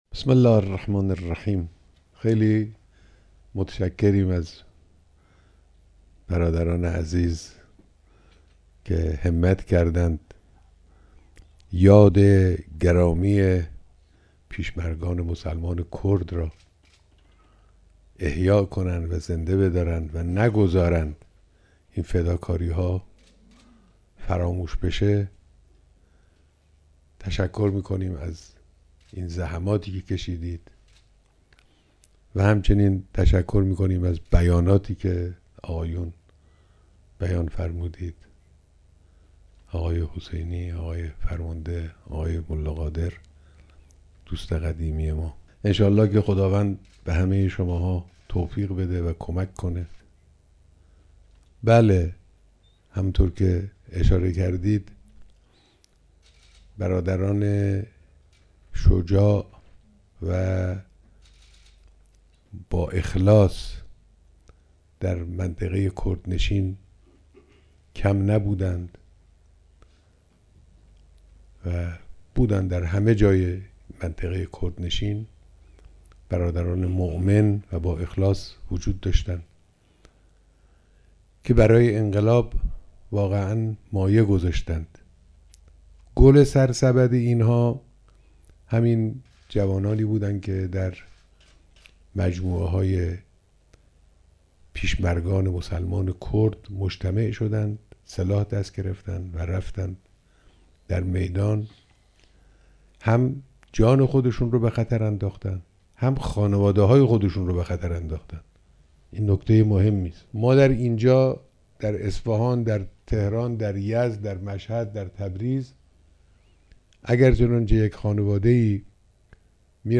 بیانات در دیدار اعضاي ستاد کنگره گرامیداشت شهدای پیشمرگان مسلمان کُرد